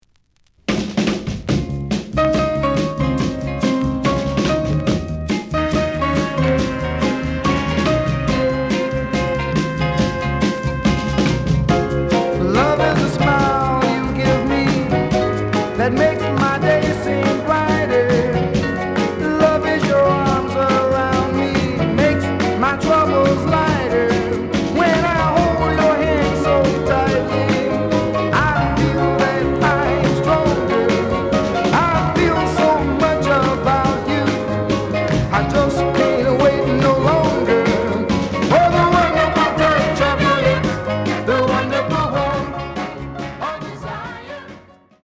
The listen sample is recorded from the actual item.
●Format: 7 inch
●Genre: Soul, 60's Soul